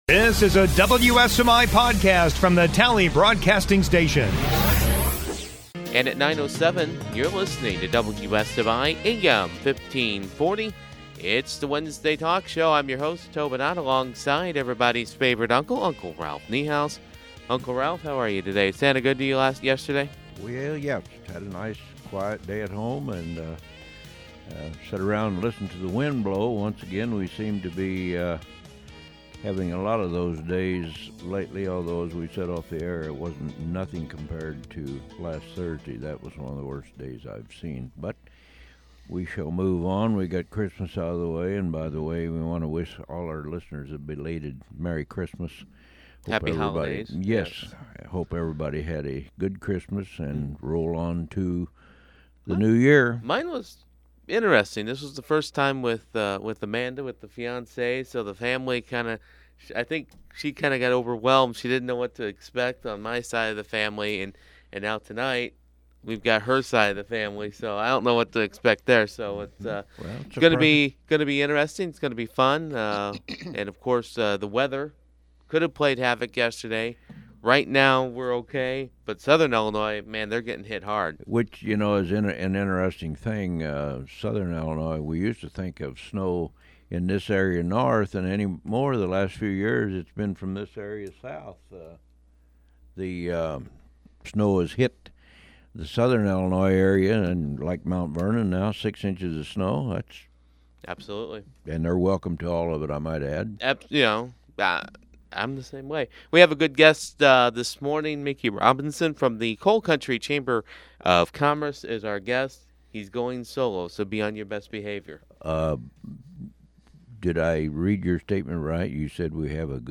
Podcasts - Wednesday Talk
Wednesday Morning Talk Show